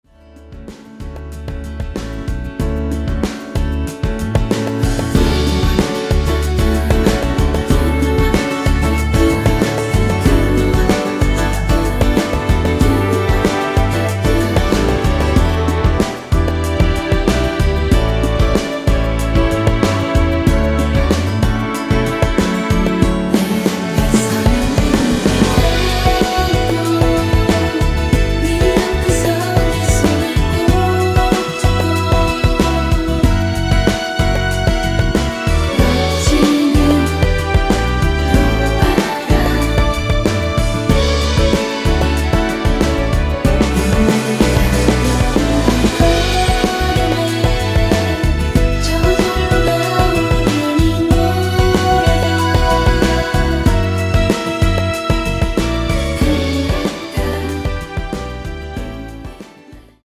원키에서(+1) 올린 코러스 포함된 MR 입니다.(미리듣기 참조)
앞부분30초, 뒷부분30초씩 편집해서 올려 드리고 있습니다.